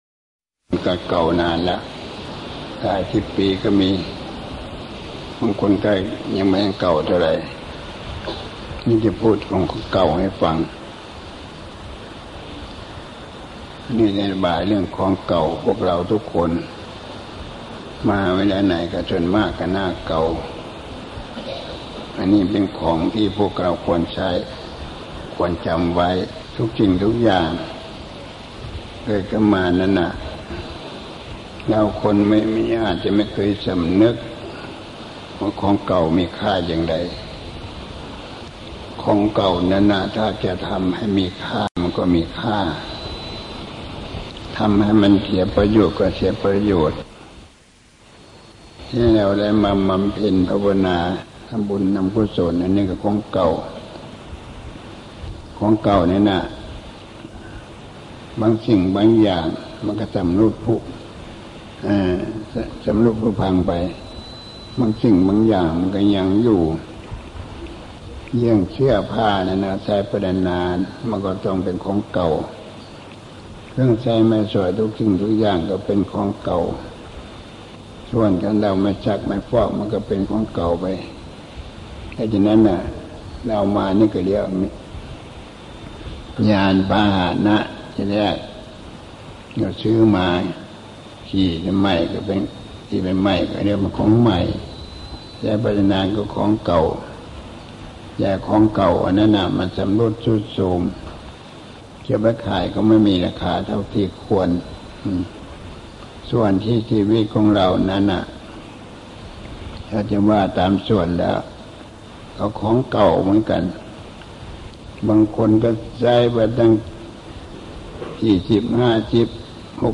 เทศน์ที่ชมรมพุทธศาสตร์วังใหม่ (กรมพลศึกษา)